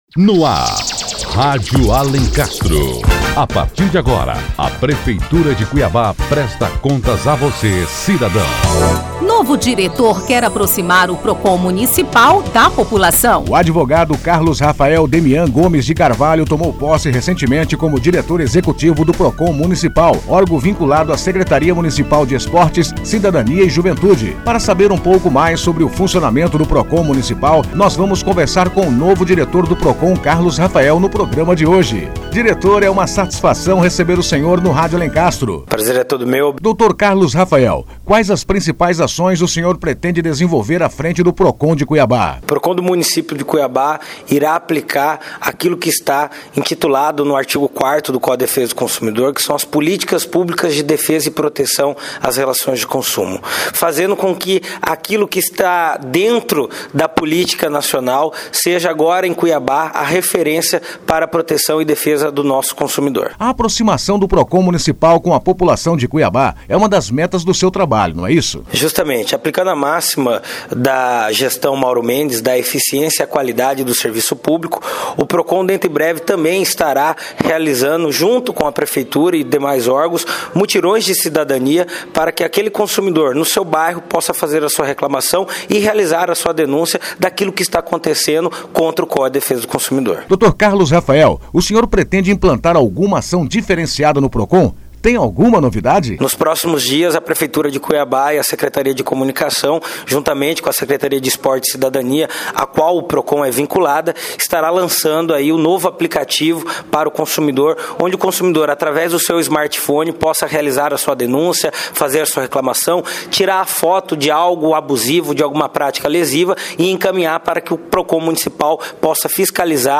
A meta principal do novo diretor é aproximar o procon municipal da população e no que tange aos direitos do consumidor prestar um atendimento de qualidade e eficiência. Confira agora a entrevista com o diretor executivo do Procon Municipal, Carlos Demian. p g r 159.mp3